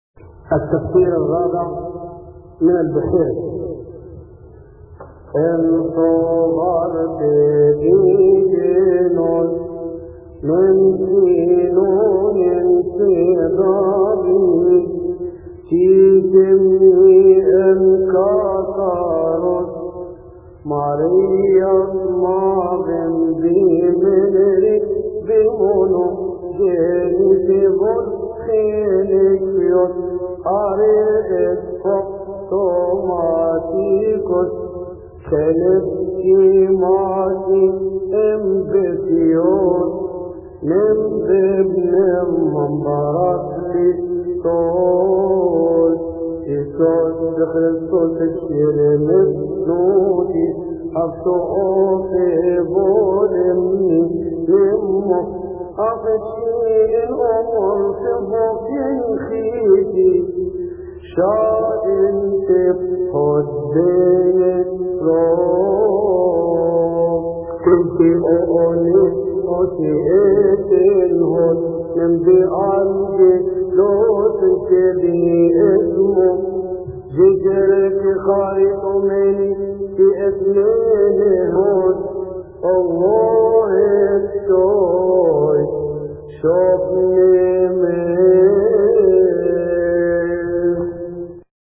المرتل
يصلي في تسبحة عشية أحاد شهر كيهك